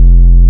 808s
SUB BOOM80.wav